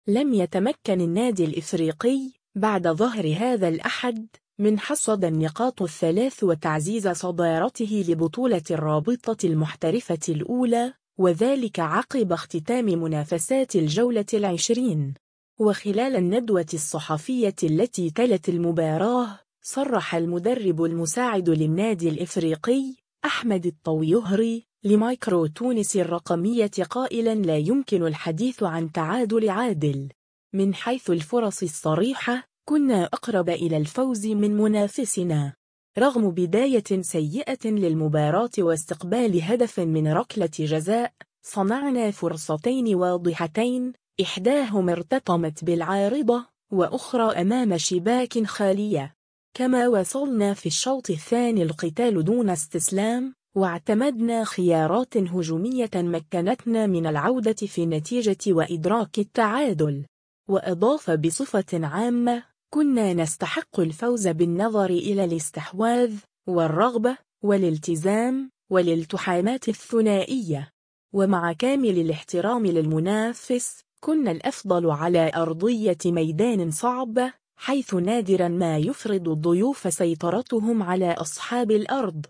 وخلال الندوة الصحفية التي تلت المباراة